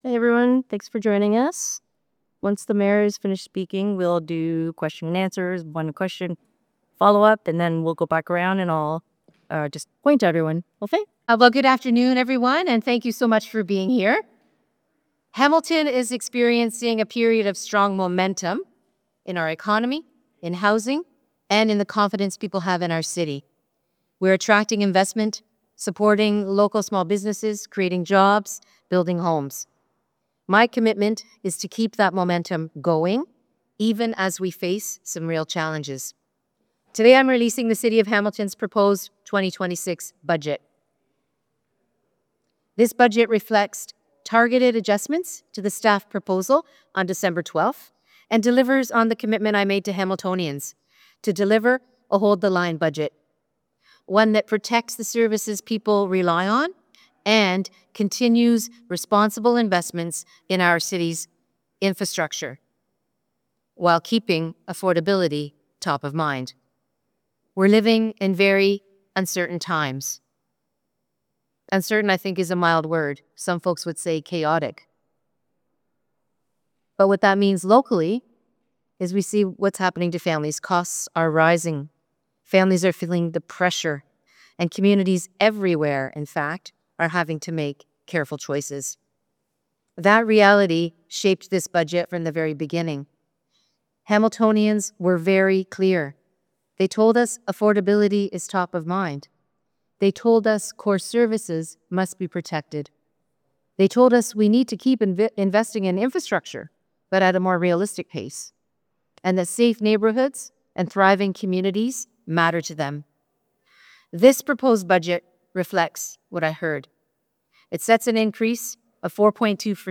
Hamilton Mayor Andrea Horwath held a press conference on Tuesday, January 22, 2026 to unveil her 2026 Mayor’s budget for the City of Hamilton.